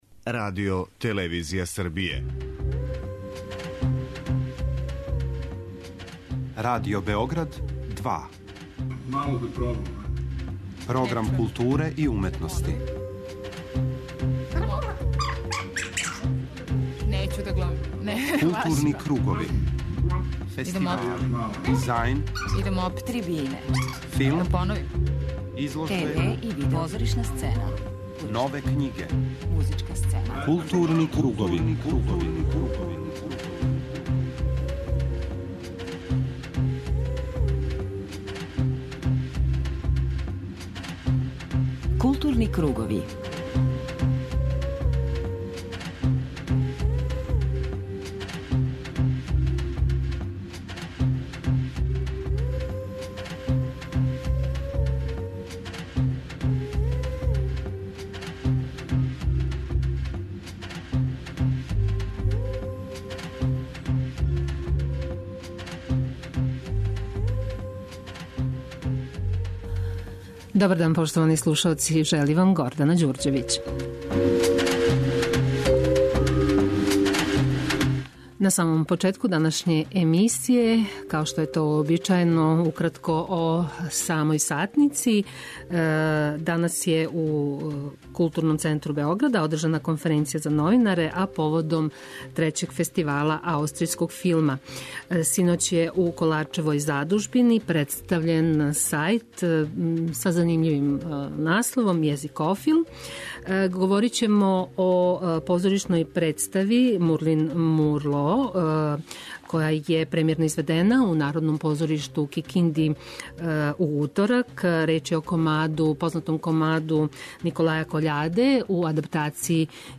преузми : 52.05 MB Културни кругови Autor: Група аутора Централна културно-уметничка емисија Радио Београда 2.